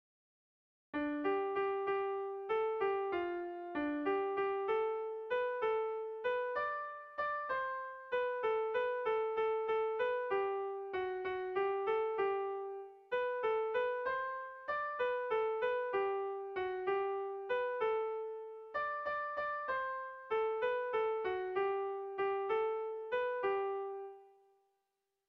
Irrizkoa
Zortziko txikia (hg) / Lau puntuko txikia (ip)
ABDE